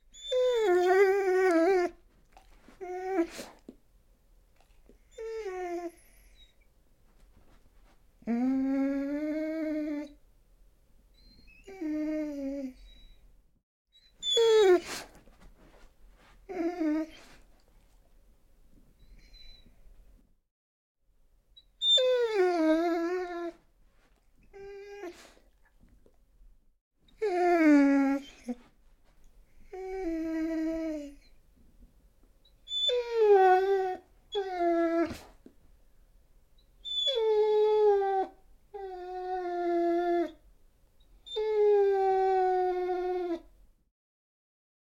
Звуки скулящей собаки
Скулещая собака - Альтернативный вариант